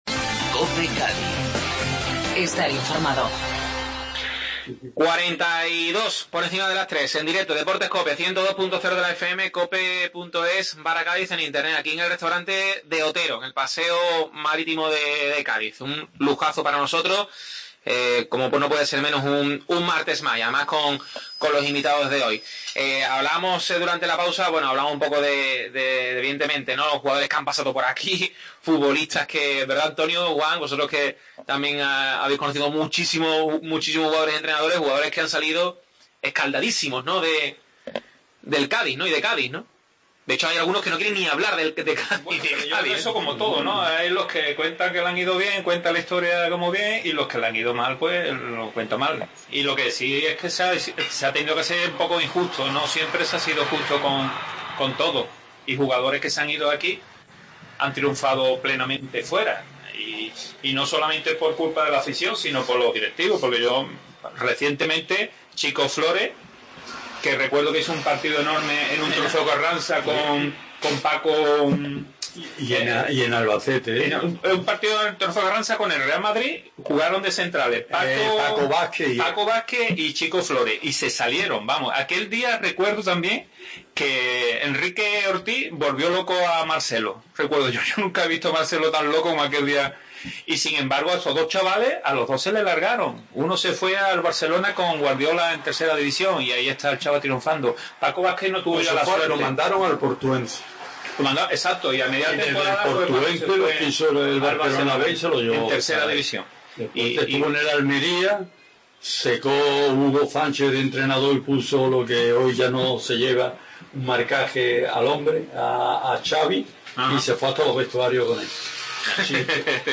AUDIO: Segunda parte de la tertulia en De Otero.